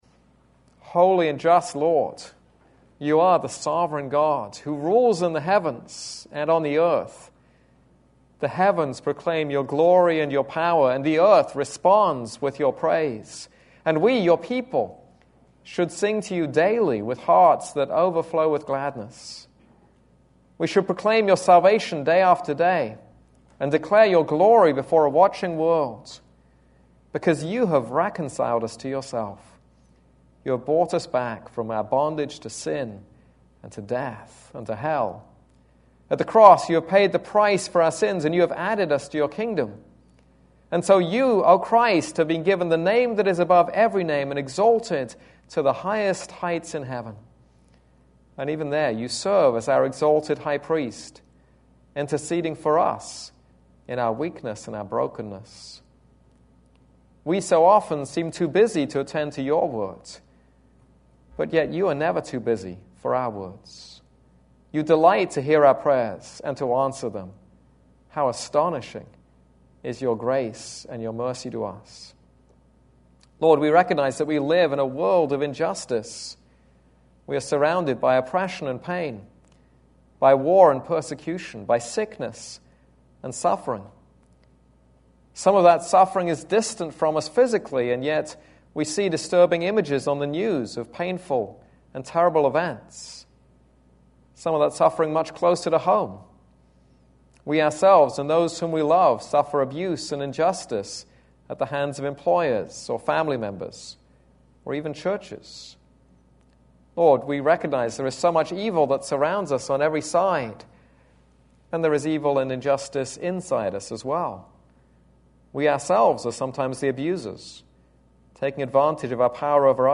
This is a sermon on Song of Songs 2:8-17.